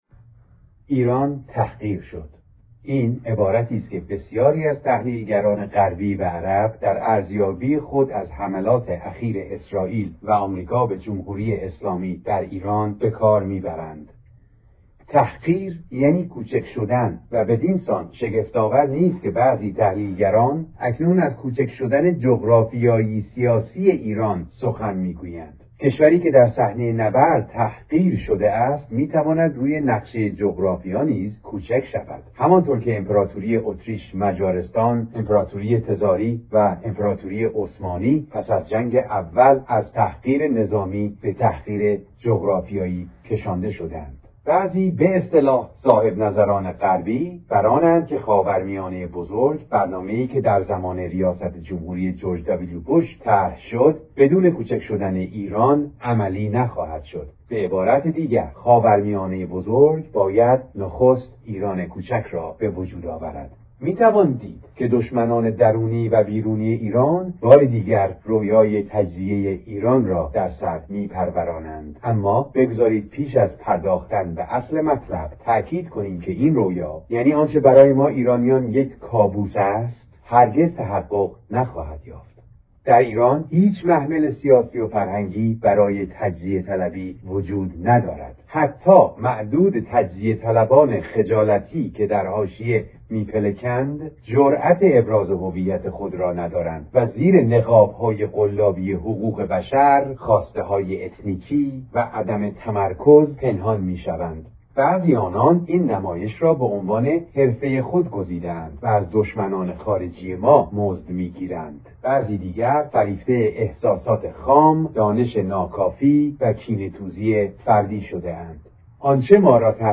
چرا ایران حرف آخر را می‌زند - امیر طاهری همانگونه که وعده‌اش را دادم، با کمال افتخار قرائت ویژه شاه مقاله دیگری را از استاد نازنین ایرانسازمان، که عمرشان دراز و به شادکامی باد، به خدمتتان تقدیم می‌کنم.